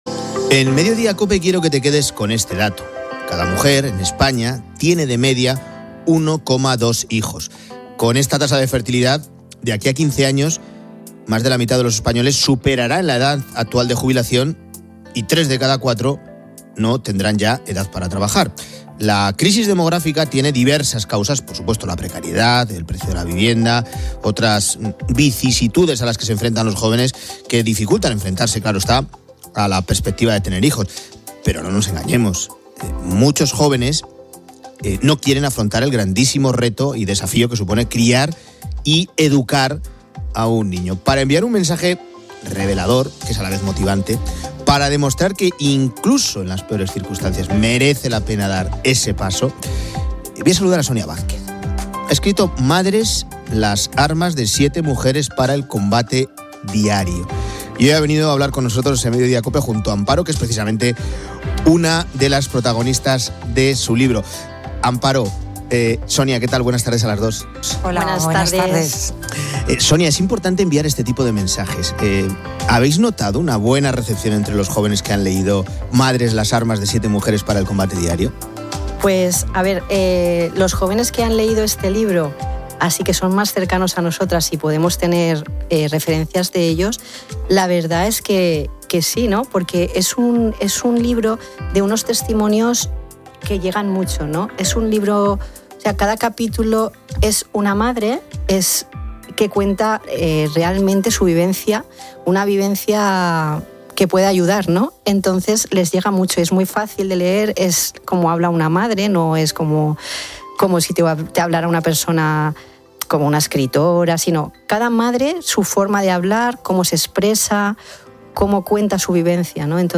Entrevista libro 'Madres'